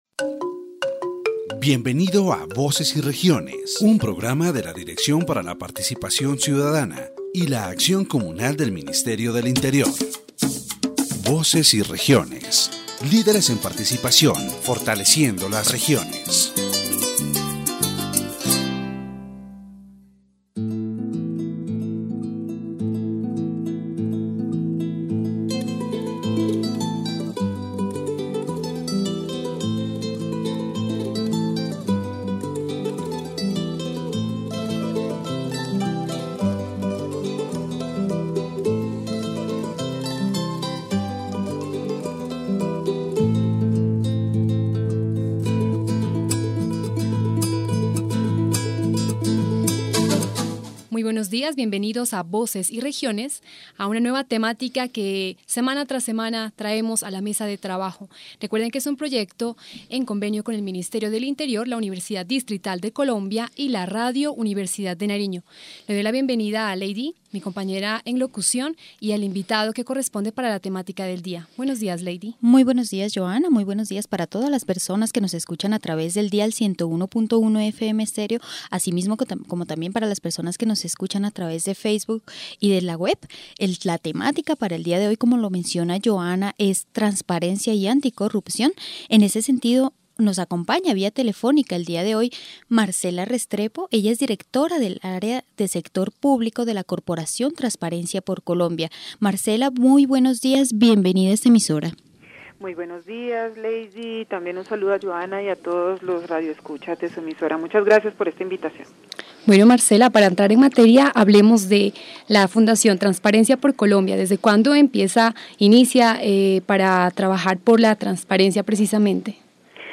The radio program "Voces y Regiones" offered an in-depth analysis of the problem of corruption in Colombia. The participants highlighted the importance of transparency and citizen participation as fundamental pillars to combat this scourge.